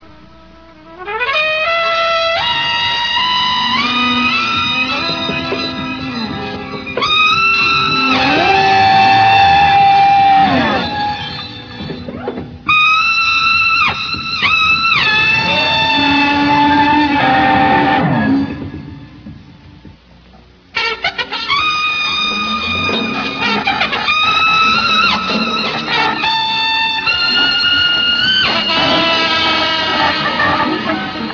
Non-Album songs that are known to have been played live: